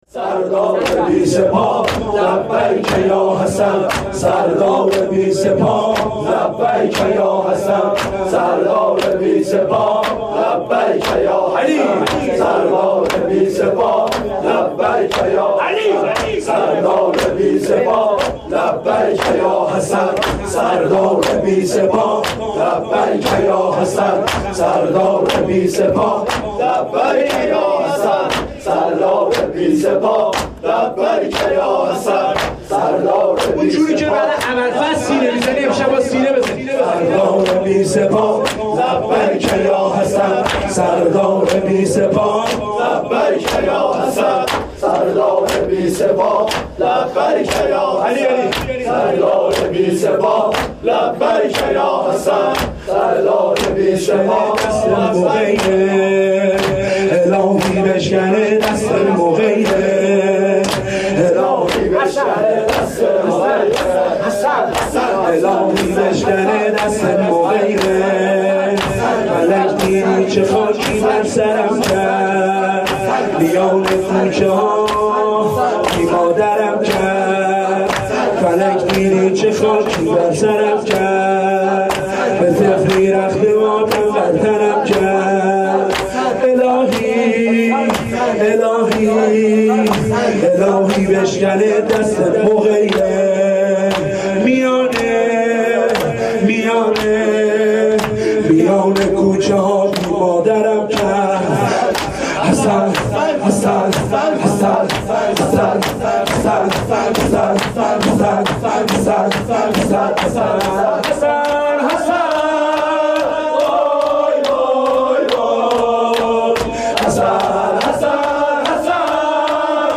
نوای فاطمیه, مداحی فاطمیه